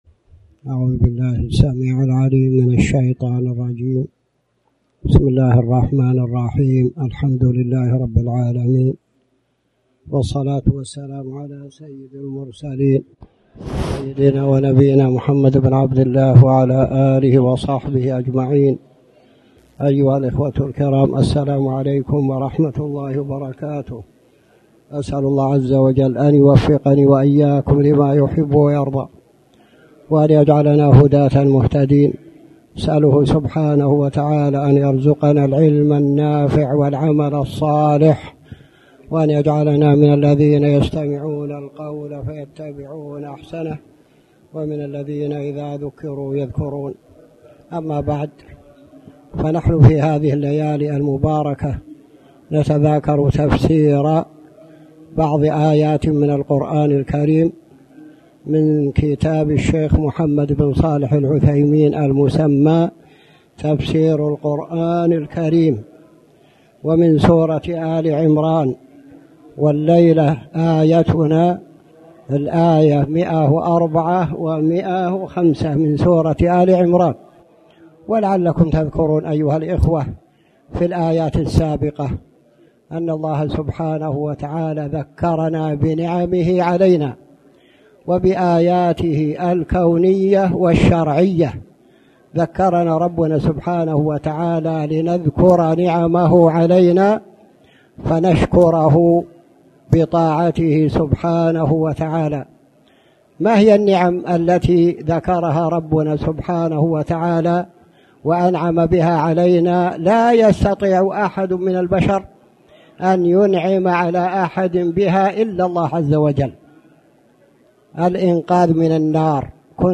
تاريخ النشر ١٨ ربيع الأول ١٤٣٩ هـ المكان: المسجد الحرام الشيخ